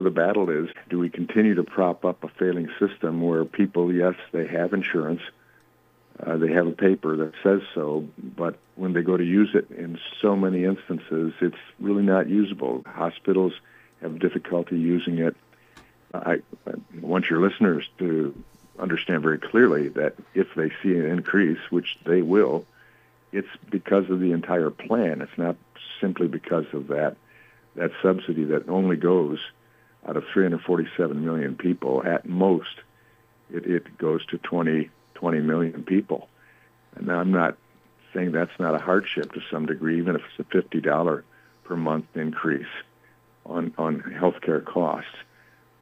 That was Congressman Tim Walberg.